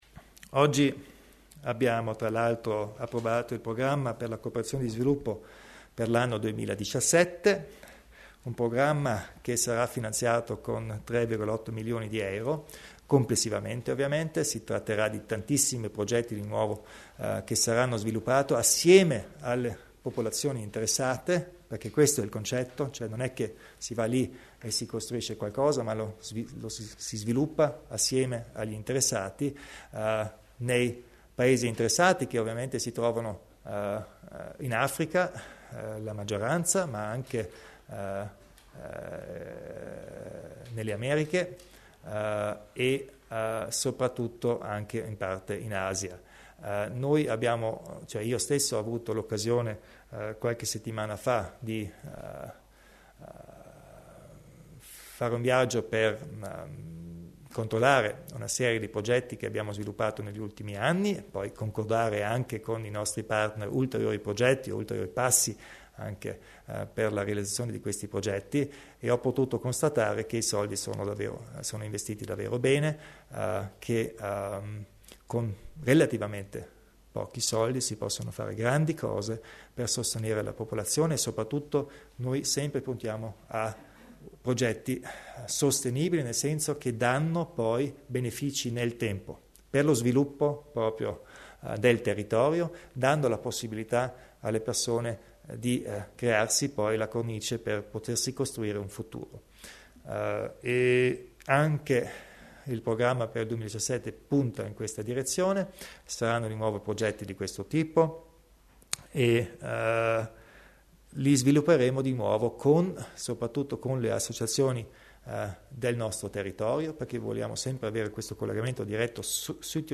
Il Presidente Kompatscher spiega i nuovi progetti di cooperazione allo sviluppo